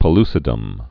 (pə-lsĭ-dəm)